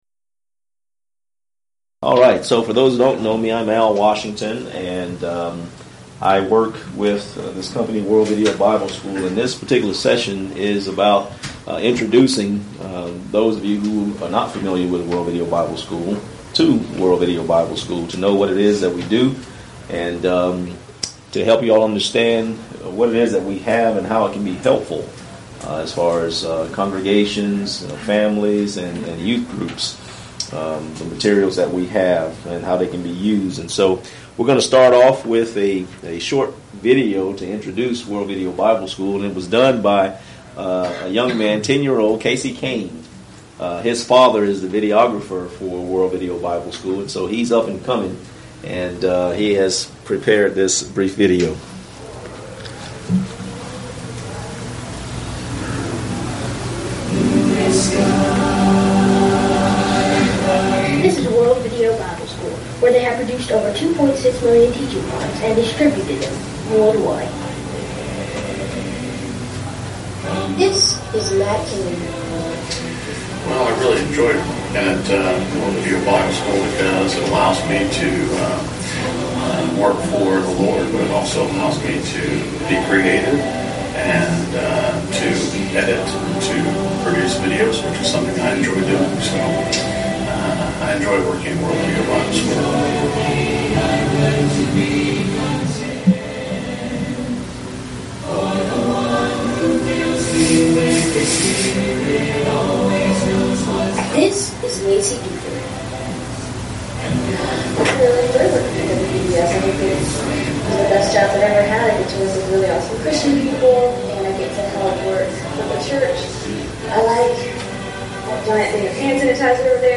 Event: 2015 Discipleship University
If you would like to order audio or video copies of this lecture, please contact our office and reference asset: 2015DiscipleshipU30